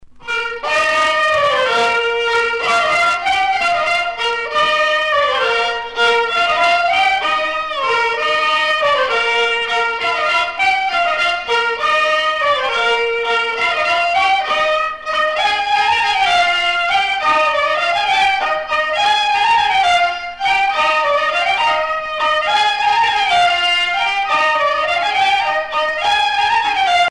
danse : parisienne
Pièce musicale éditée